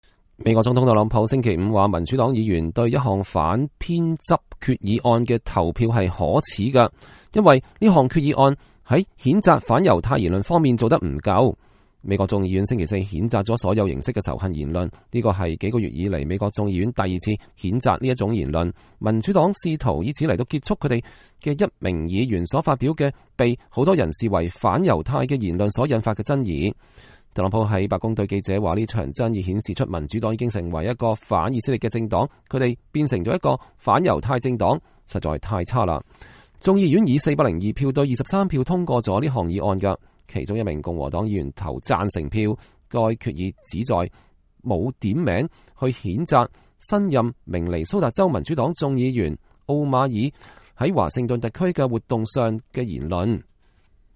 特朗普總統在啟程前往阿拉巴馬州之前在白宮外對記者講話。(2019年3月8日)